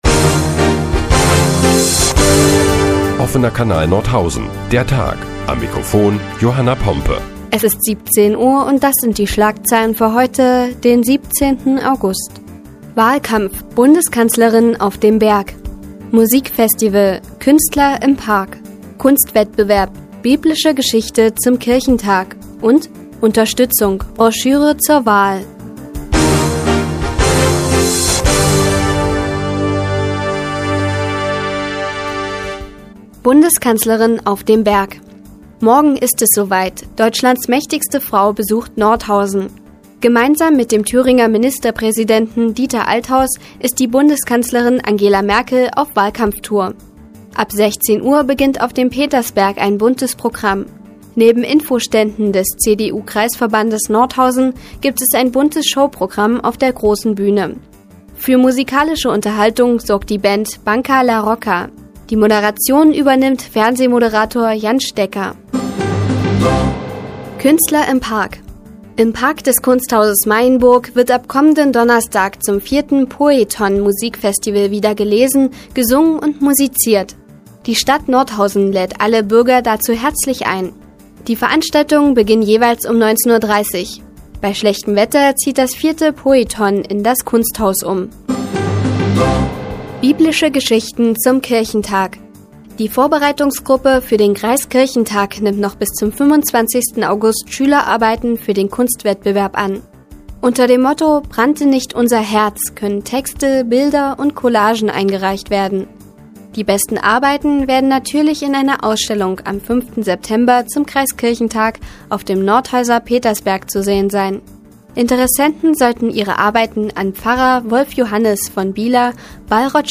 Die tägliche Nachrichtensendung des OKN ist nun auch in der nnz zu hören. Heute geht es unter anderem um die mächtigste Frau Deutschlands auf dem Petersberg und um einen Kunstwettbewerb zum Kirchentag.